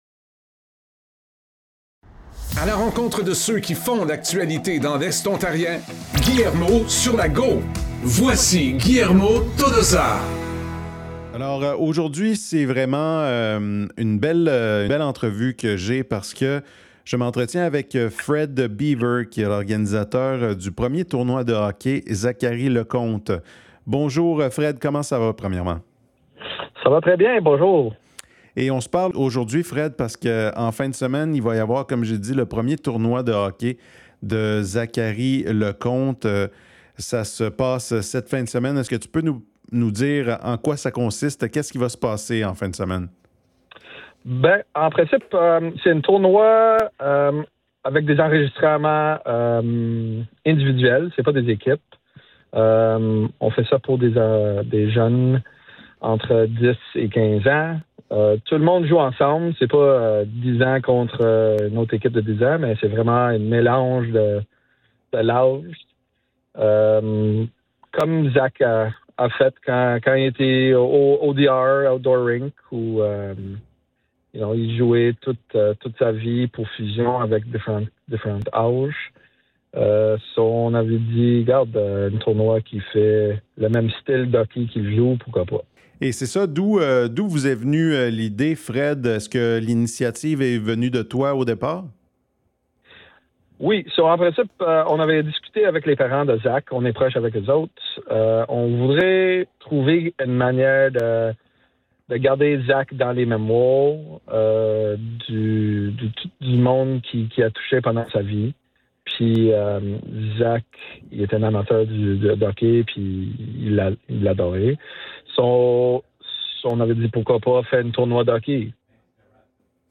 Cette semaine, j’ai reçu en entrevue